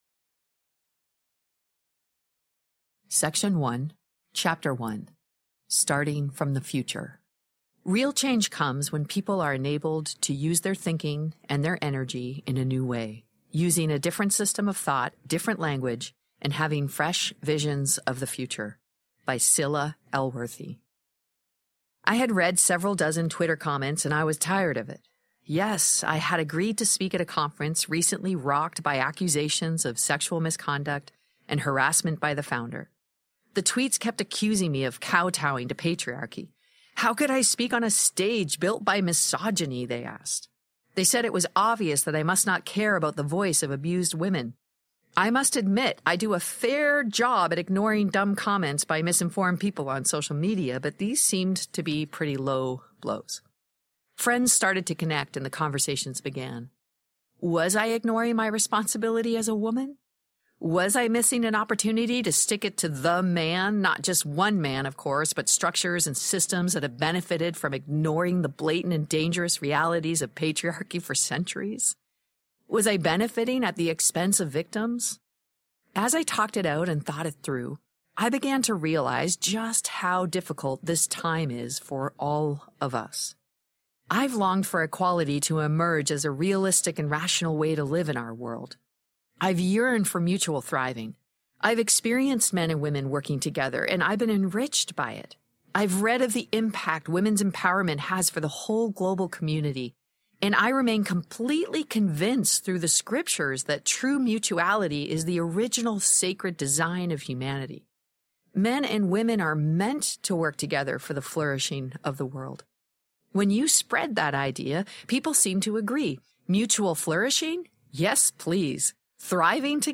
Better Together Audiobook